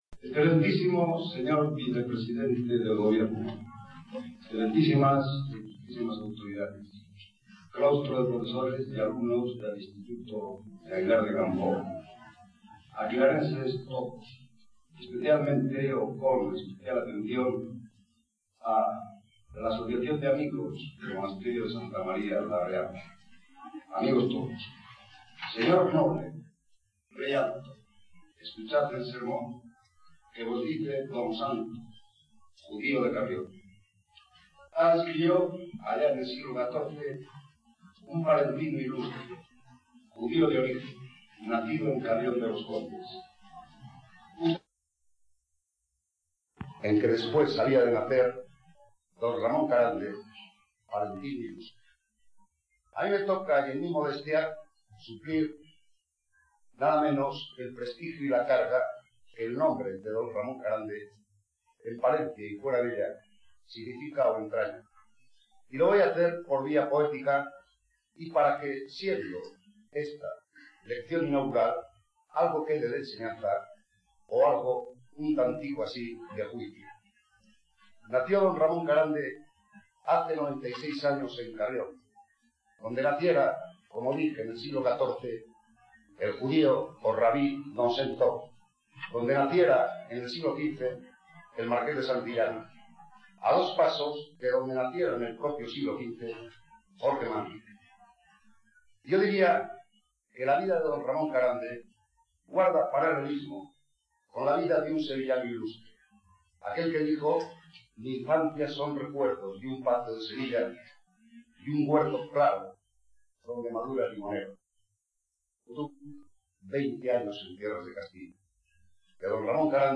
CONFERENCIAS
Inauguración del Instituto de Aguilar del Campoo (Santa María la Real ) - 1986